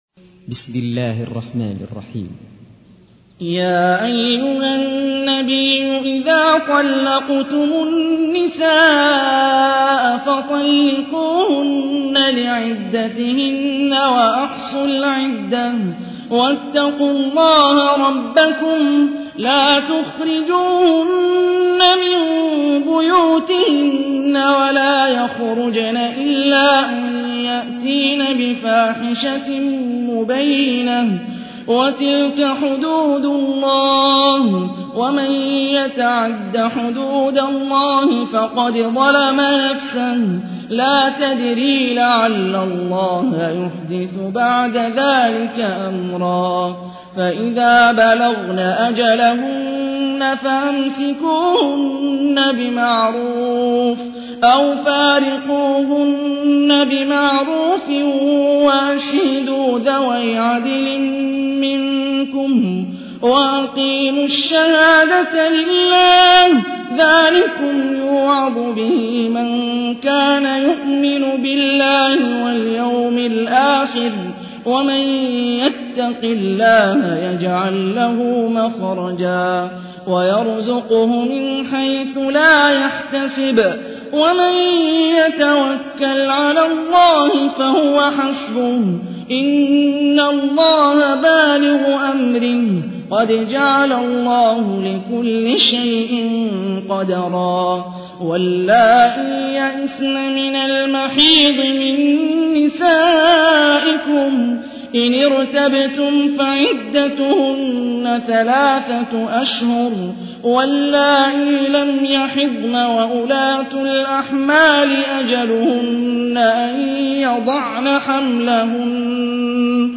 تحميل : 65. سورة الطلاق / القارئ هاني الرفاعي / القرآن الكريم / موقع يا حسين